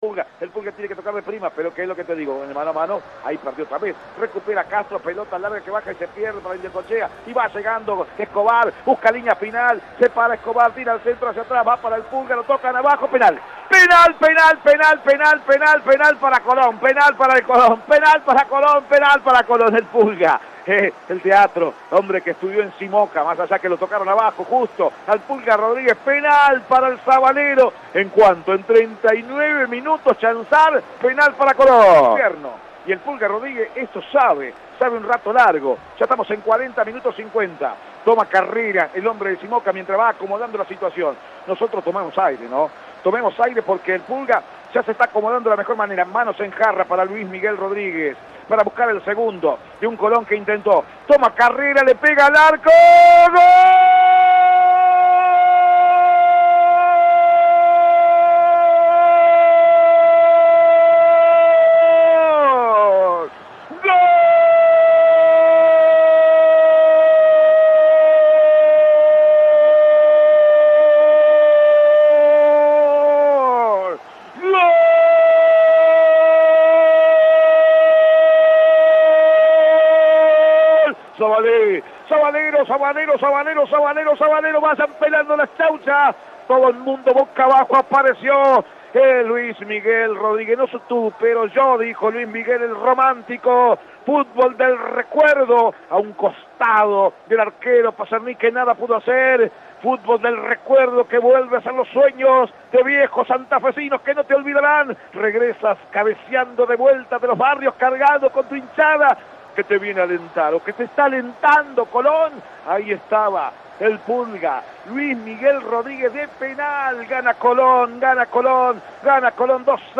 Con transmisión de Radio EME: Colón ganó y mantiene puntaje ideal